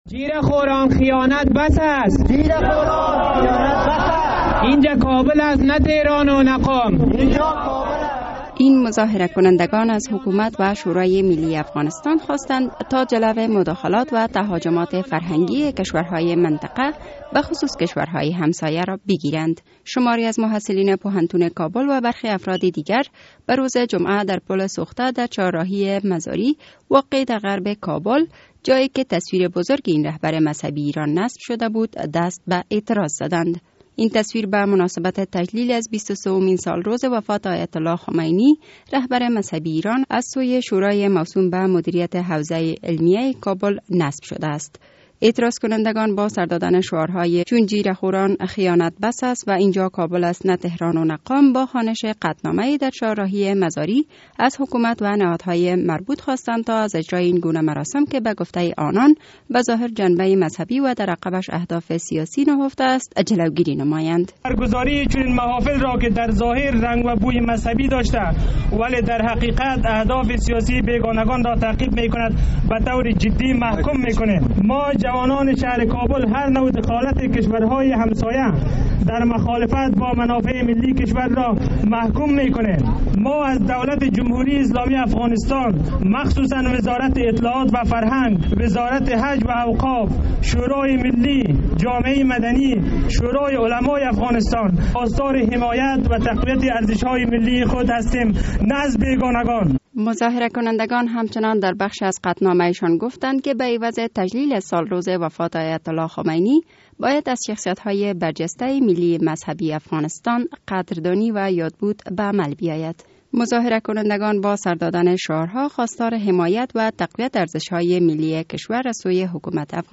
گزارش بخش افغانستان رادیو اروپای آزاد/رادیو آزادی از تظاهرات کابل